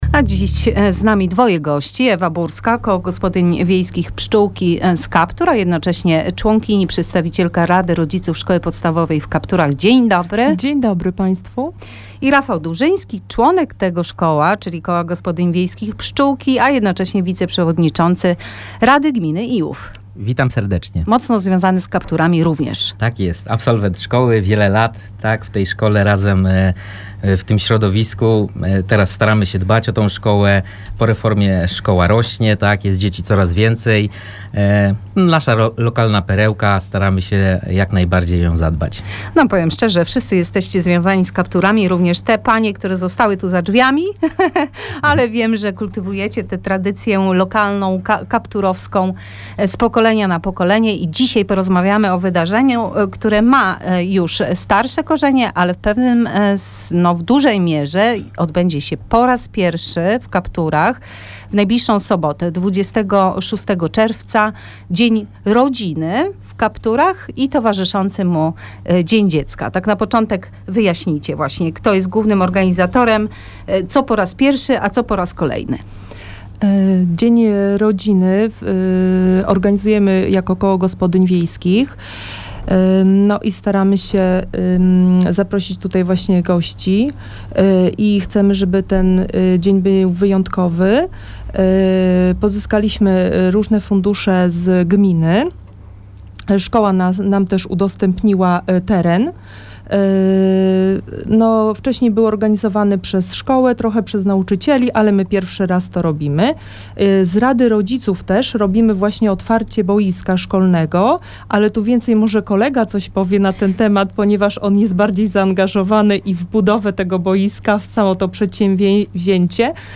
Wywiad z Wiceprzewodniczącym Rady Gminy Rafałem Durzyńskim